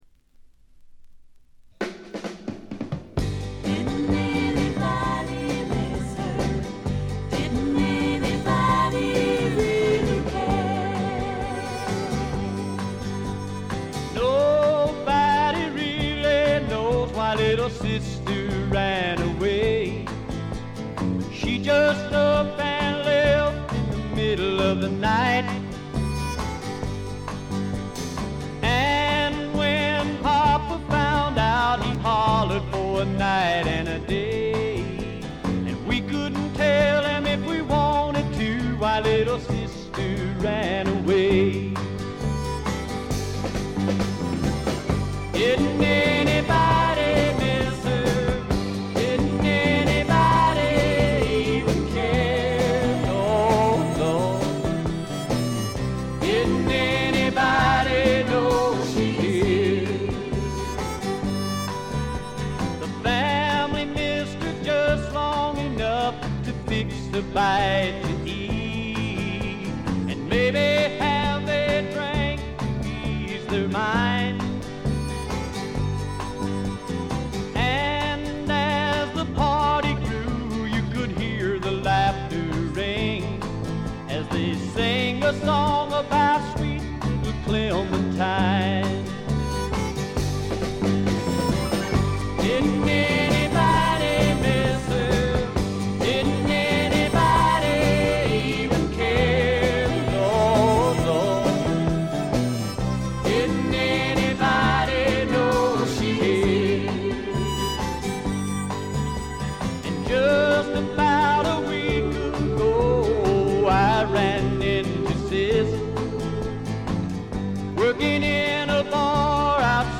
ごくわずかなノイズ感のみ。
謎のシンガー・ソングライター好盤です。
時代を反映してか、ほのかに香る土の匂いがとてもいい感じですね。
試聴曲は現品からの取り込み音源です。
Engineered At - Gold Star Studios